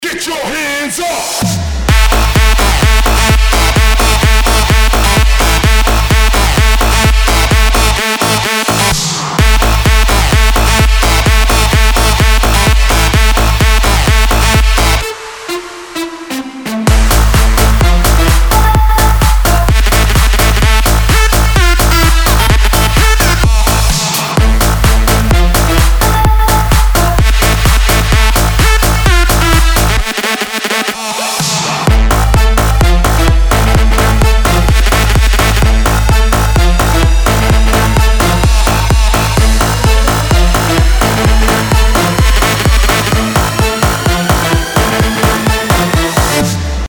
• Качество: 320, Stereo
Датч Хаус Музыка